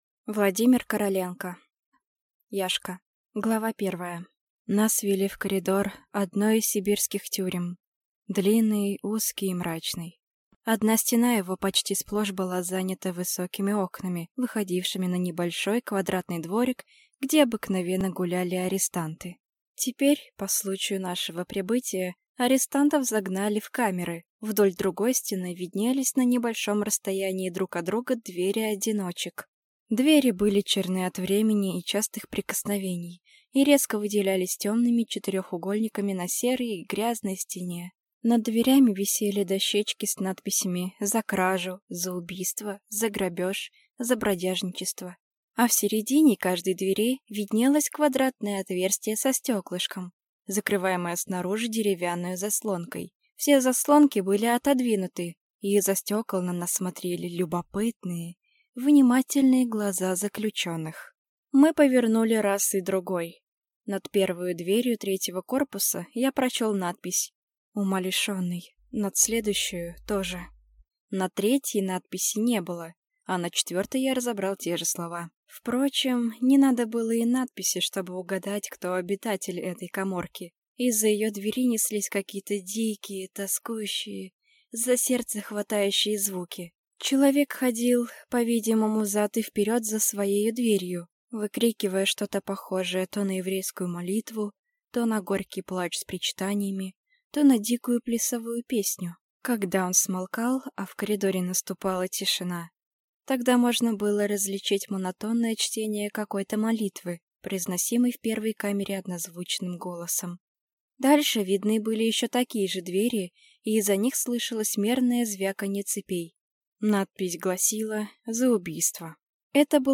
Аудиокнига Яшка | Библиотека аудиокниг